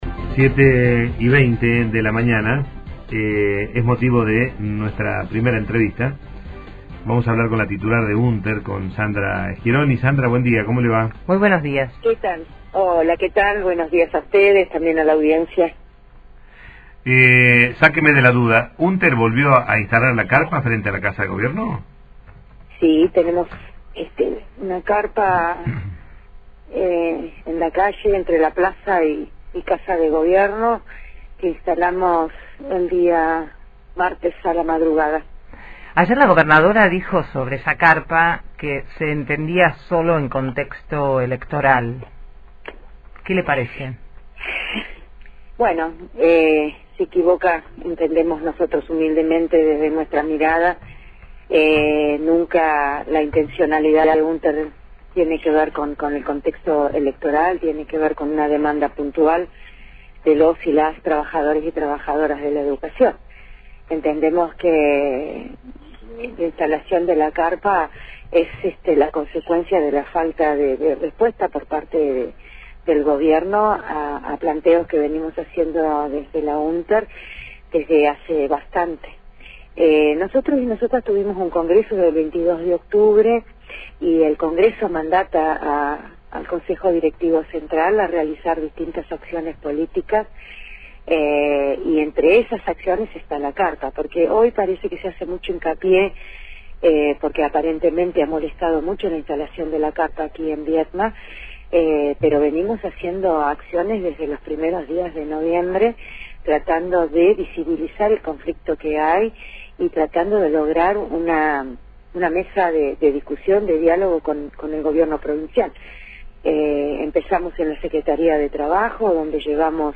Entrevista en radio